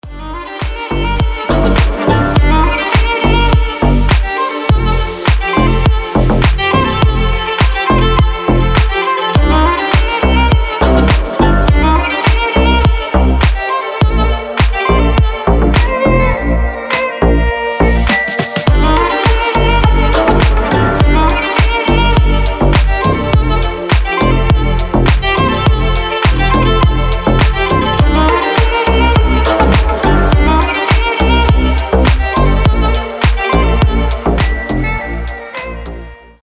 • Качество: 320, Stereo
красивые
deep house
без слов
скрипка
чувственные
восточные
Хорошая композиция с восточным вайбом